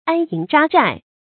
注音：ㄢ ㄧㄥˊ ㄓㄚ ㄓㄞˋ
安營扎寨的讀法